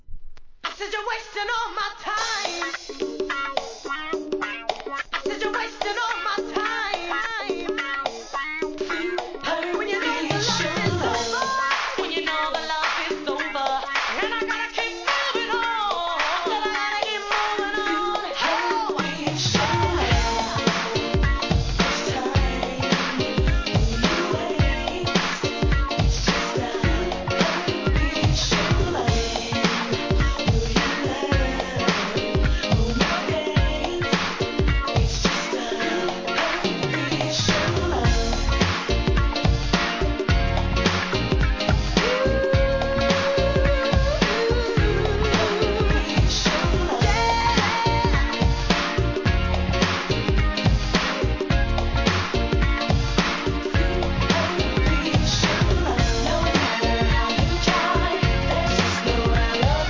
HIP HOP/R&B
ガラージ・ハウス・クラシックとしても人気ですがNEW JACK SWING調な爽やかソングも！！